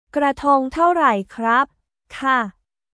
クラトン タオライ クラップ/カー